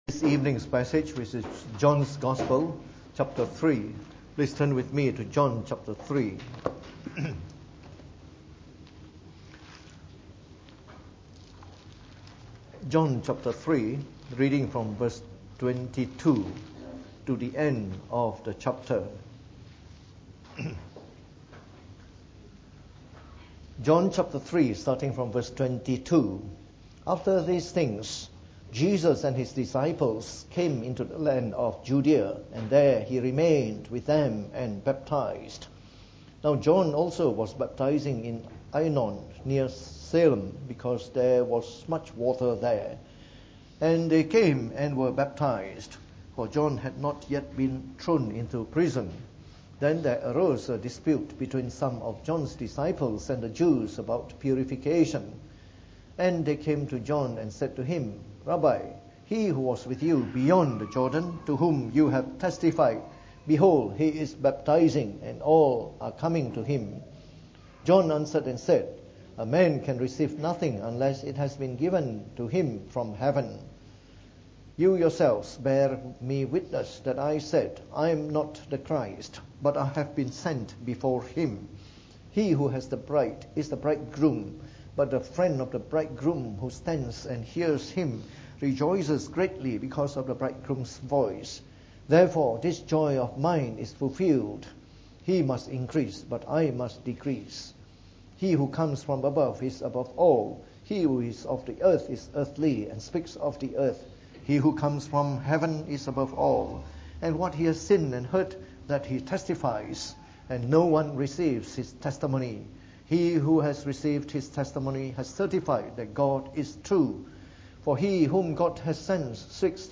From our series on the Gospel of John delivered in the Evening Service.